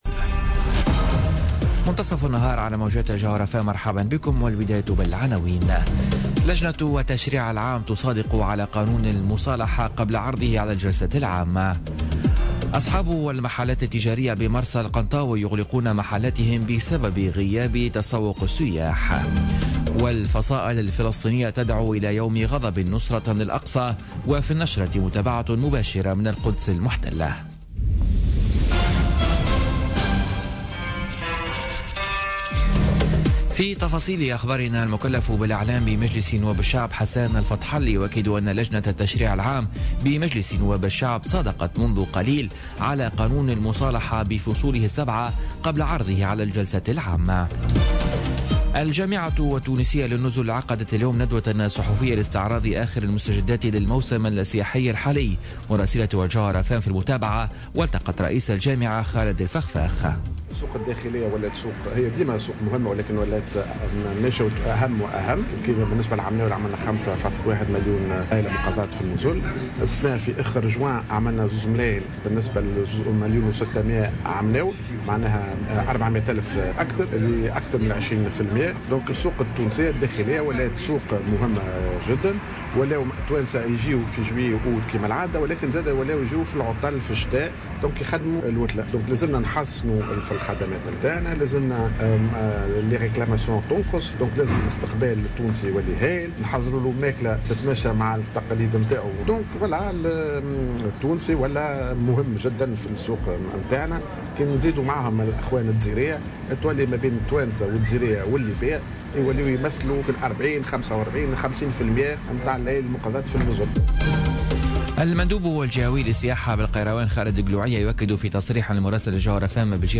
نشرة أخبار منتصف النهار ليوم الثلاثاء 19 جويلية 2017